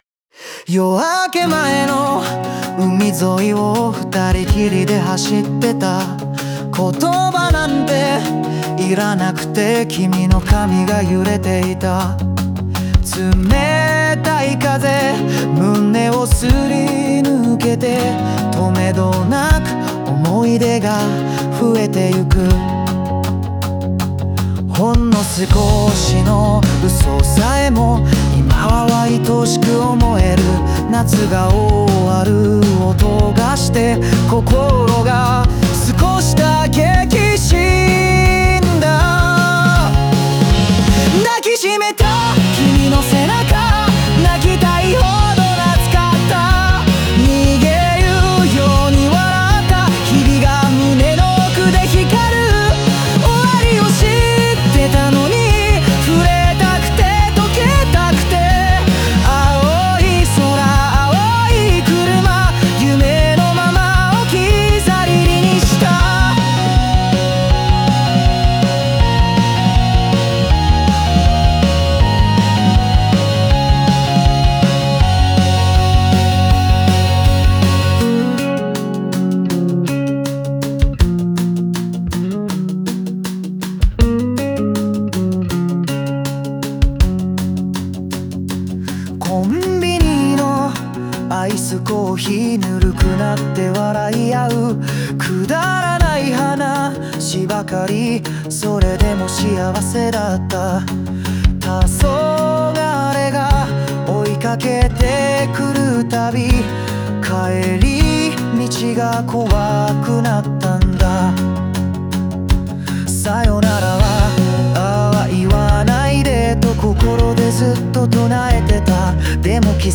オリジナル曲♪
ノスタルジックな音の風景とともに、忘れられない恋の記憶を丁寧に綴った一曲です。